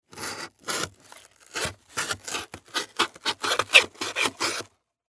Звуки картонной коробки
Звук разрезания картонной коробки канцелярским ножом